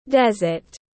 Sa mạc tiếng anh gọi là desert, phiên âm tiếng anh đọc là /ˈdez.ət/.
Để đọc đúng tên tiếng anh của sa mạc rất đơn giản, các bạn chỉ cần nghe phát âm chuẩn của từ desert rồi nói theo là đọc được ngay.